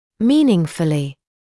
[‘miːnɪŋfəlɪ] [‘миːнинфэли] значимо; многозначительно